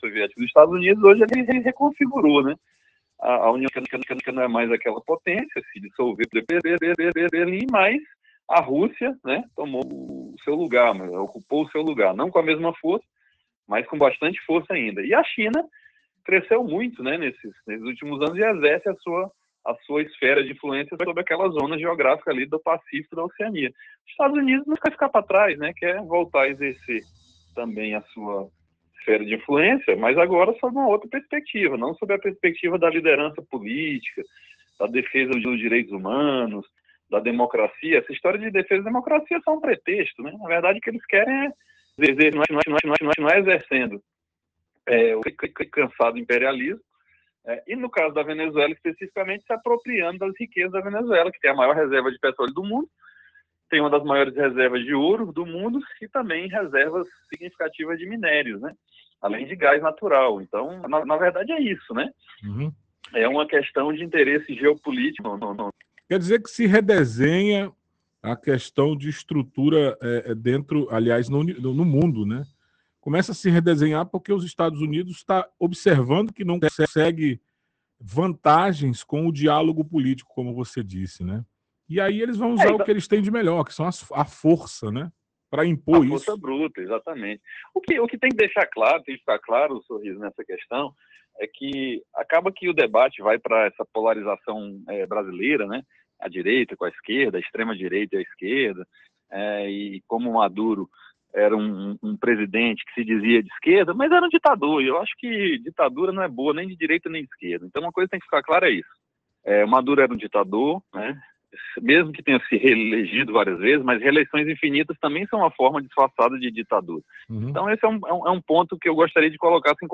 na Rádio Avalanche FM, o ex-deputado estadual e professor universitário Daniel Zen fez uma análise crítica sobre o atual cenário geopolítico mundial, com foco na Venezuela e na atuação das grandes potências internacionais, especialmente os Estados Unidos.